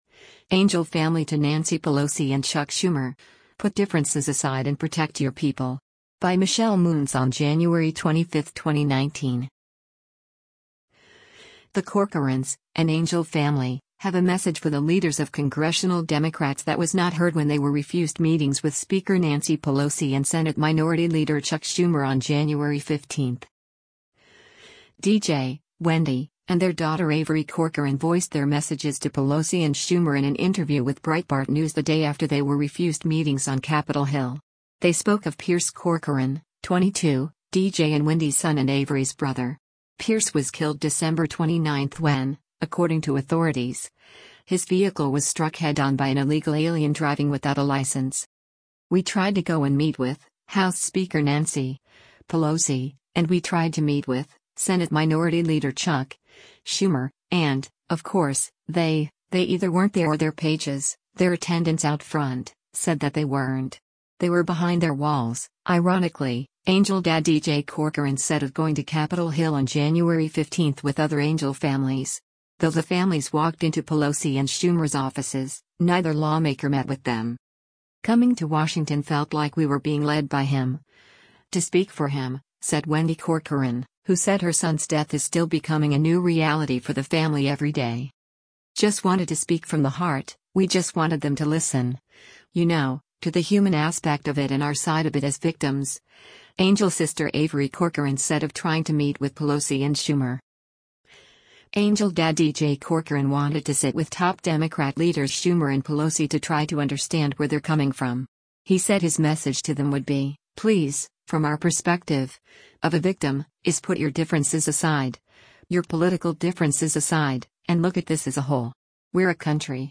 Washington, DC